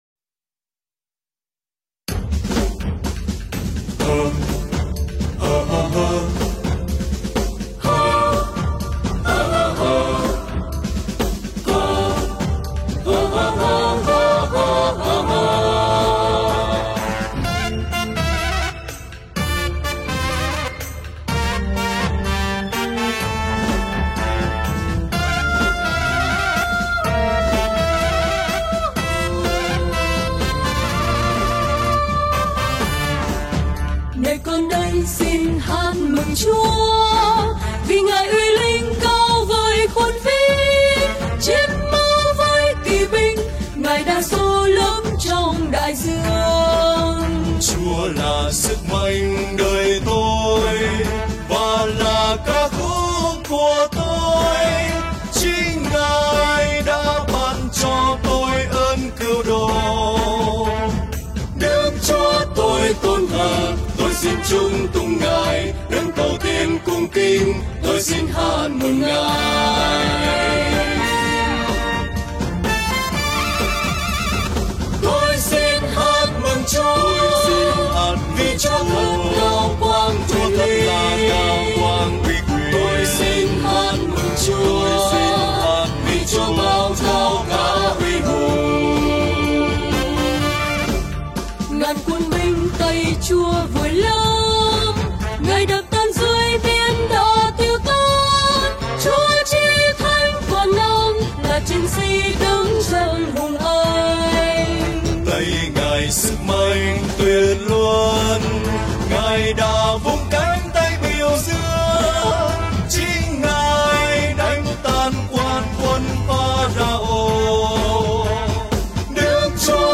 Thể hiện bởi: Tốp ca